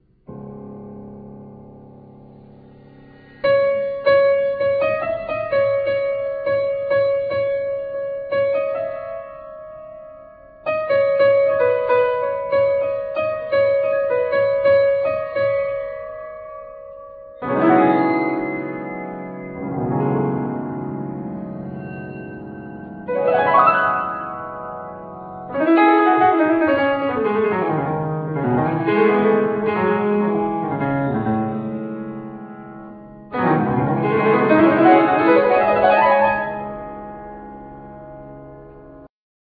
Piano
Violin
Cello
Tuba
Percussins